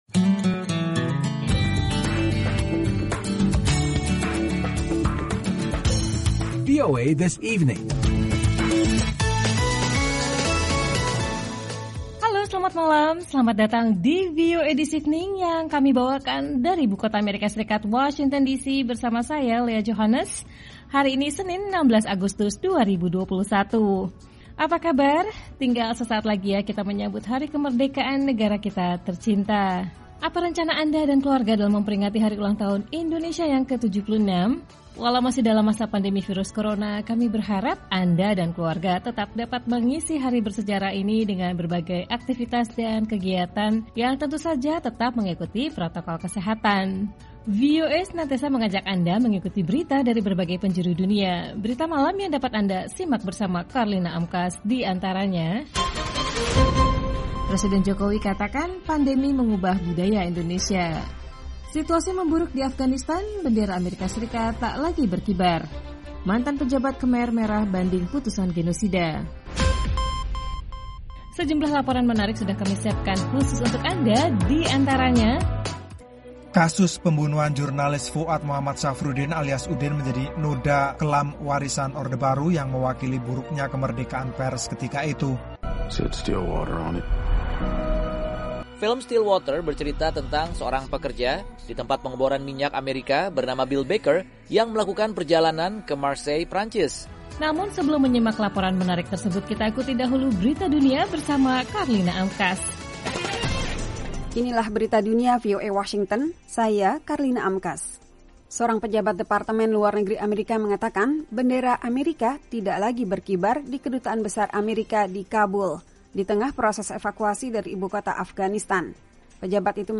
Siaran VOA This Evening